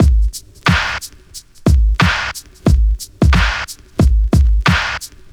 • 90 Bpm 2000s Drum Beat G# Key.wav
Free breakbeat - kick tuned to the G# note. Loudest frequency: 856Hz